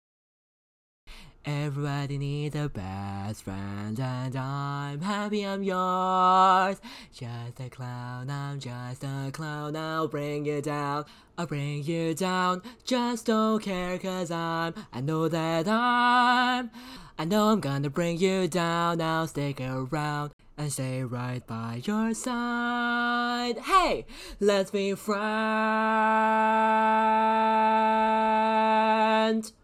Type: Barbershop
Each recording below is single part only.